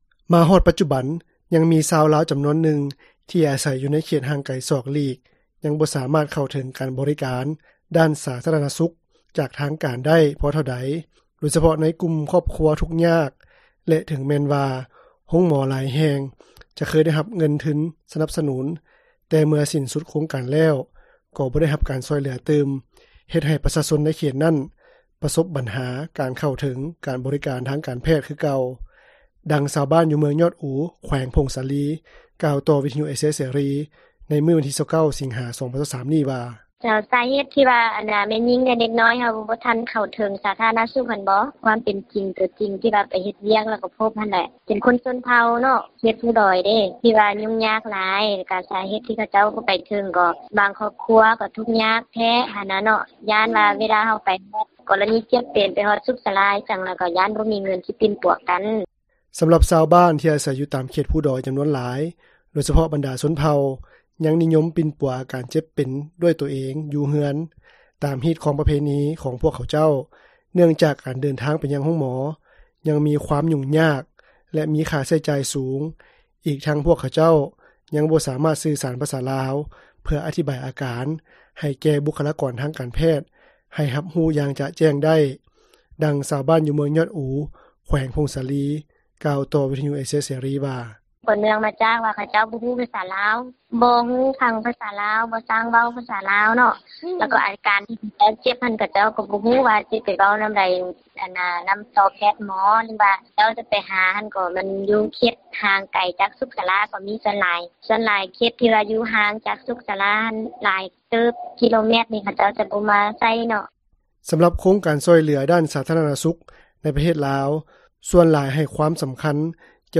ດັ່ງເຈົ້າໜ້າທີ່ ຢູ່ໂຮງໝໍນ້ອຍ ແຫ່ງນຶ່ງ ໃນແຂວງຜົ້ງສາລີ ນາງນຶ່ງ ກ່າວວ່າ:
ທີ່ຜ່ານມາ, ໂຮງໝໍຊຸມຊົນ ແລະ ໂຮງໝໍເມືອງ ຫຼາຍແຫ່ງ ທົ່ວປະເທດລາວ ຍັງປະສົບບັນຫາຂາດແຄນ ບຸກຄະລາກອນທາງການແພດ ທີ່ມີຄວາມຮູ້ສະເພາະດ້ານ ເຮັດໃຫ້ພໍ່ແມ່ປະຊາຊົນ ທີ່ເດີນທາງໄປໃຊ້ບໍຣິການ ທາງການແພດ ຍັງບໍ່ທັນໄດ້ຮັບຄຳແນະນຳ ທີ່ແນ່ນອນ ຕໍ່ອາການເຈັບປ່ວຍ ແລະ ການປິ່ນປົວ ຢ່າງມີຄຸນນະພາບເທື່ອ. ດັ່ງເຈົ້າໜ້າທີ່ ຢູ່ໂຮງໝໍເມືອງ ແຫ່ງນຶ່ງ ທາງພາກເໜືອ ຂອງລາວ ກ່າວວ່າ:
ດັ່ງຊາວບ້ານ ຢູ່ເມືອງຈຳພອນ ແຂວງສວັນນະເຂດ ກ່າວວ່າ: